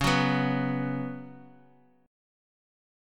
C#7sus4 chord